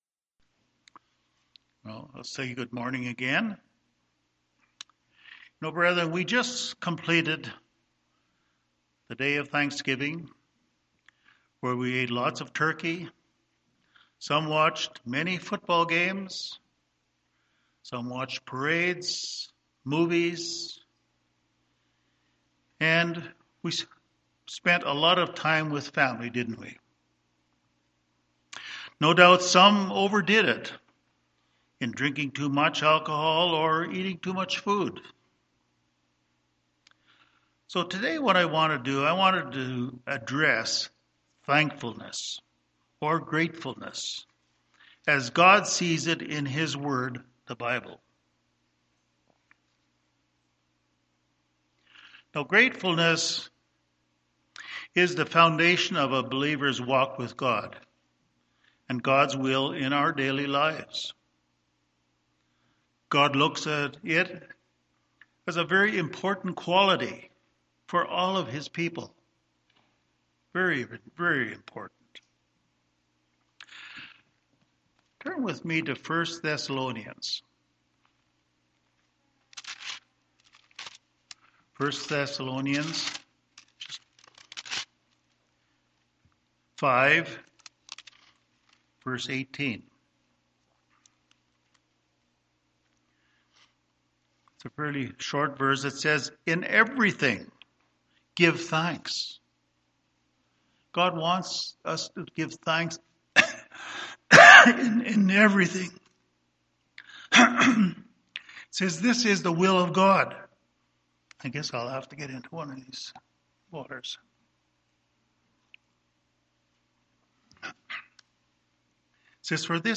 This sermon addresses gratefulness/thankfulness as God sees it in His Word, the Bible.
Given in Denver, CO Colorado Springs, CO